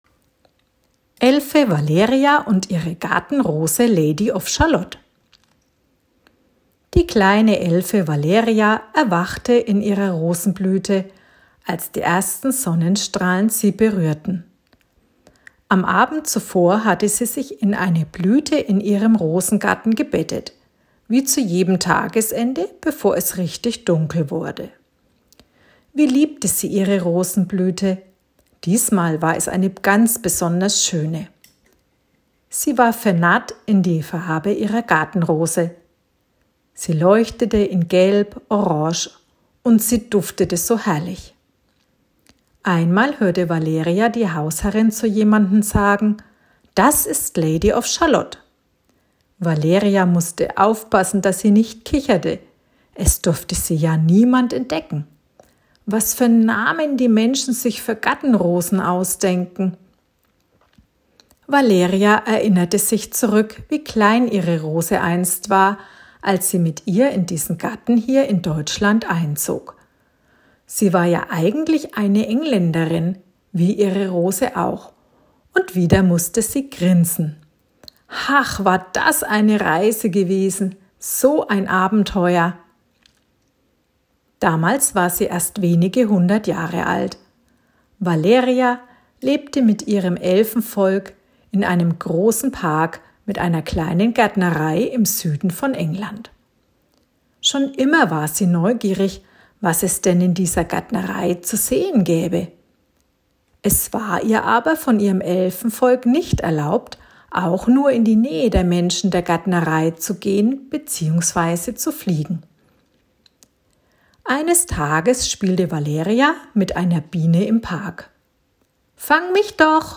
Die Elfengeschichte: Elfe Valeria und ihre Gartenrose 'Lady of Shalott' als Geschichte und kleines Hörbuch.